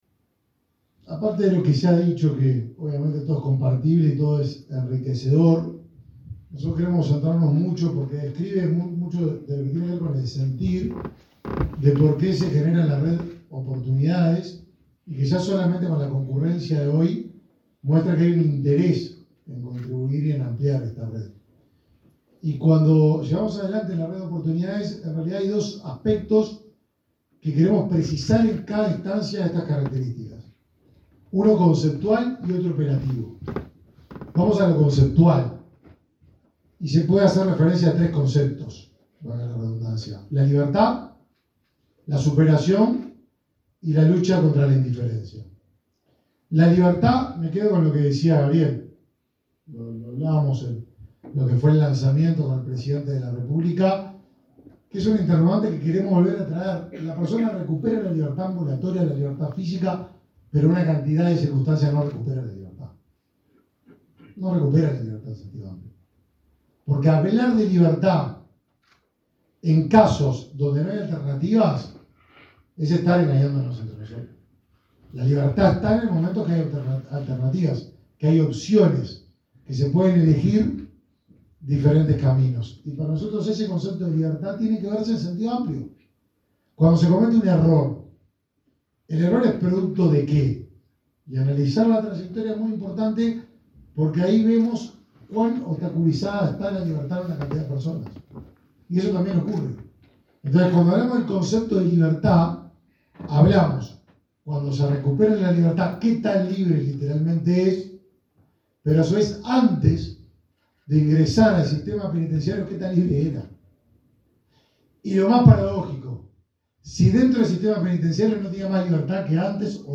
Palabras del ministro de Desarrollo Social, Martín Lema
El ministro de Desarrollo Social, Martín Lema, participó este martes 20 en el Primer Encuentro de la Red de Oportunidades, instancia para continuar